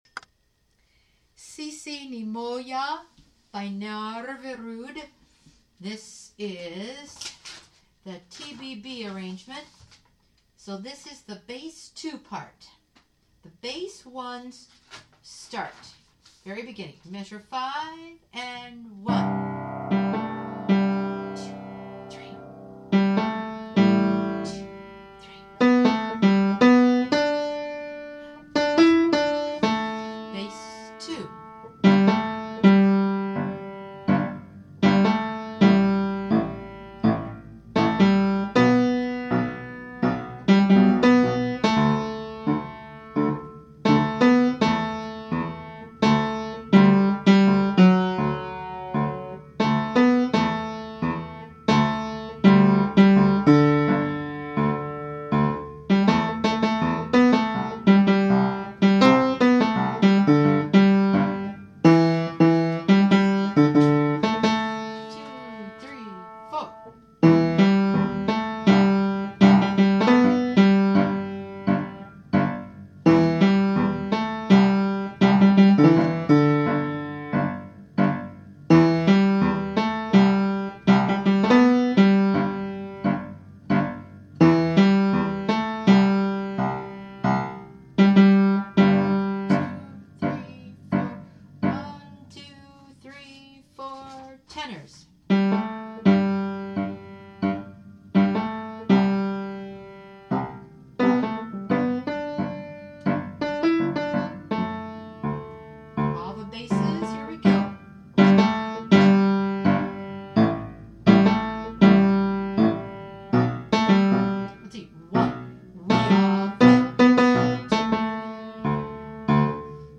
03-Sisi-Ni-Moja-TBB-B2.mp3